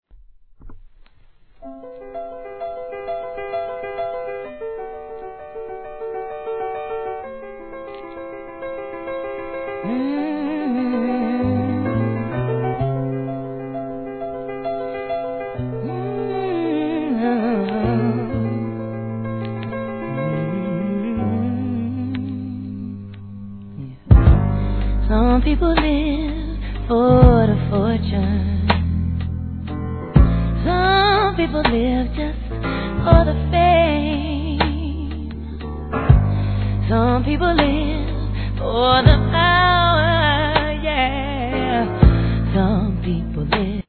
HIP HOP/R&B
皆が大好き極上スロ〜ジャム☆